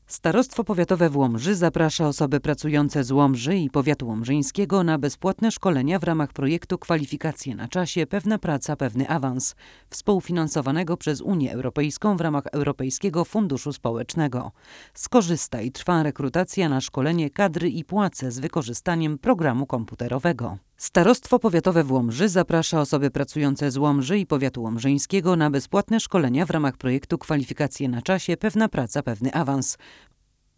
Nagranie wokal żeński